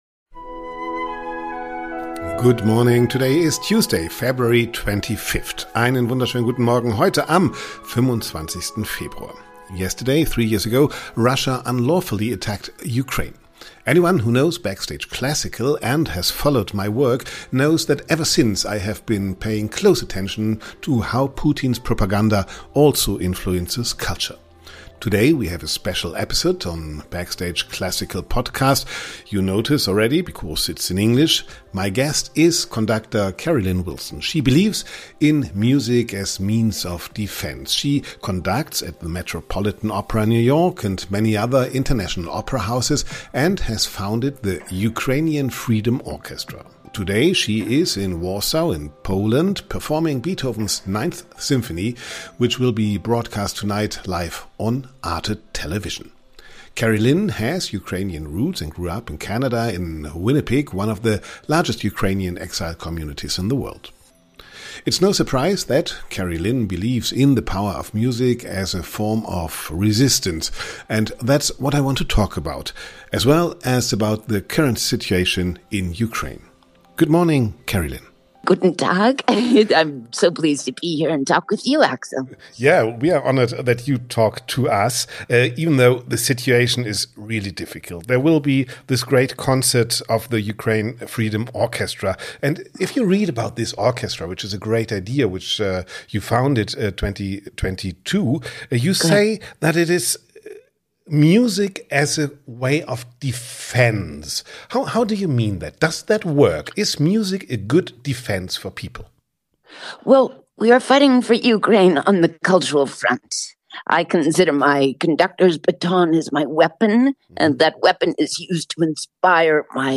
Im Backstage Classical Podcasts geht es um den russischen Angriff auf die Ukraine vor drei Jahren. Der Podcast beleuchtet, wie Putin-Propaganda die Kultur beeinflusst. Im Gespräch mit Dirigentin Keril-Lynn Wilson wird die Rolle der Musik als Verteidigungsmittel erörtert.